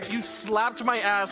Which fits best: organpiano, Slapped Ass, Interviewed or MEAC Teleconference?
Slapped Ass